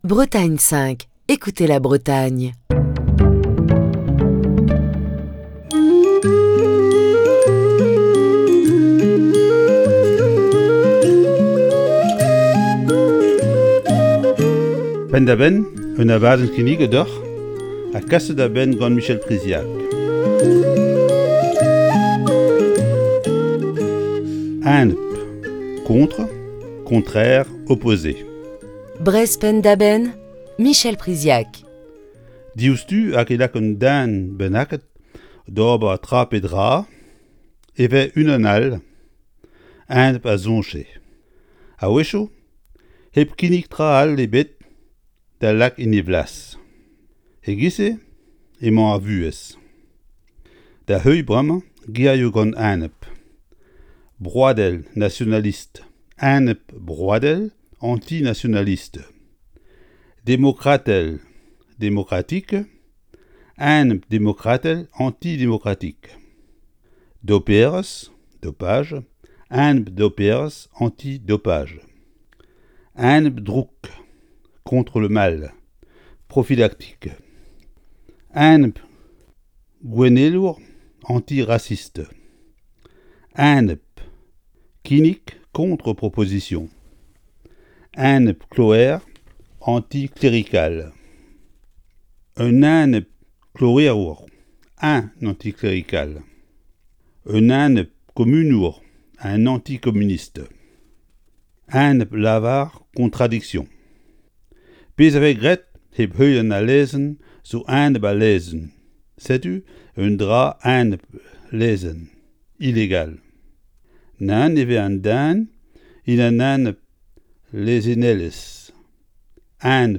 Chronique du 16 septembre 2021.